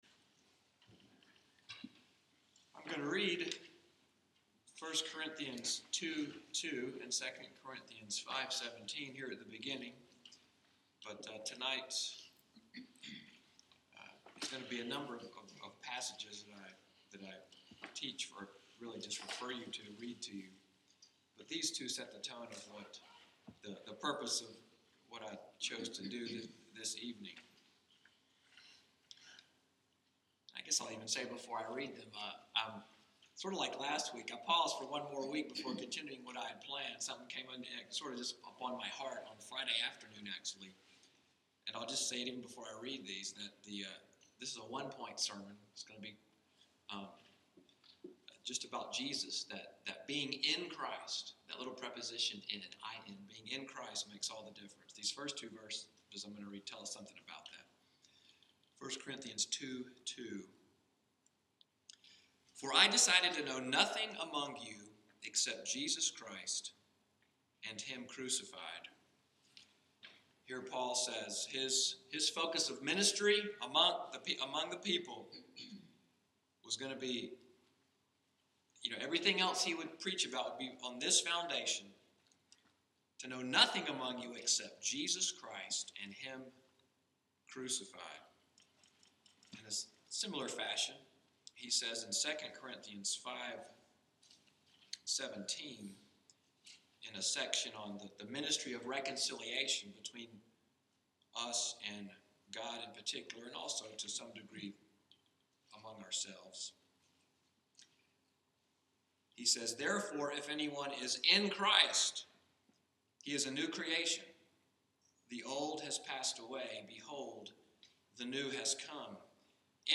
February 28, 2016 EVENING DISCIPLESHIP Sermon, “IN CHRIST.”